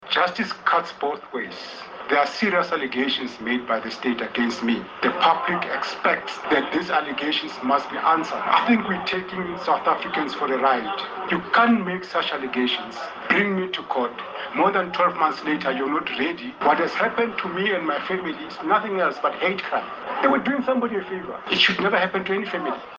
Koko het buite die hof gesê die regstappe teen hom was ‘n misdaad en ‘n poging om sy gesin te vernietig: